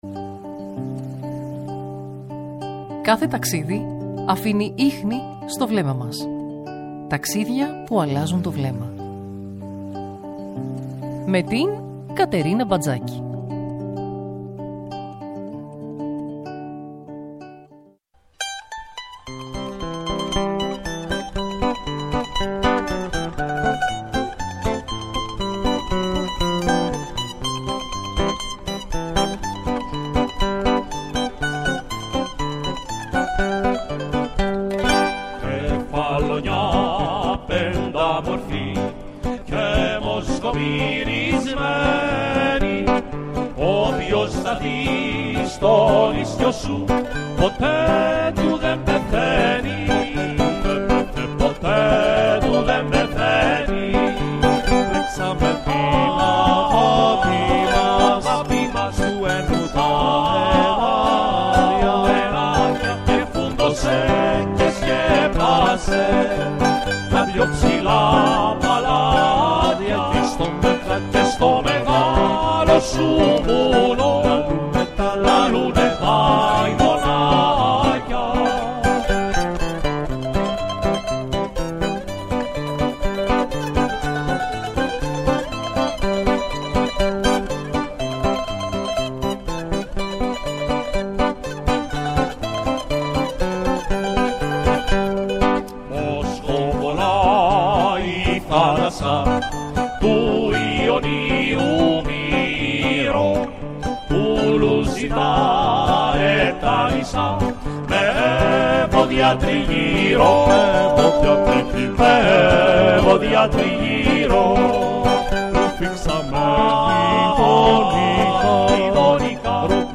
φιλοξένησε στο στούντιο